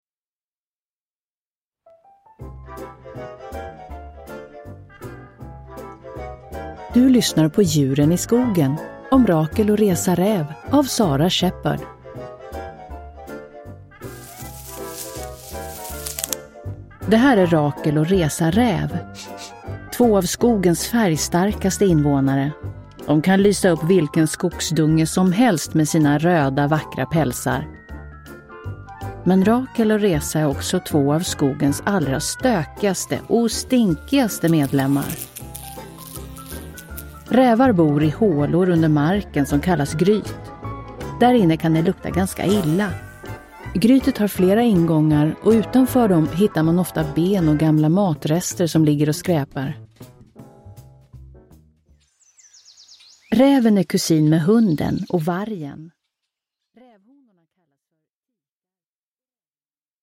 Rakel och Reza Räv – Ljudbok – Laddas ner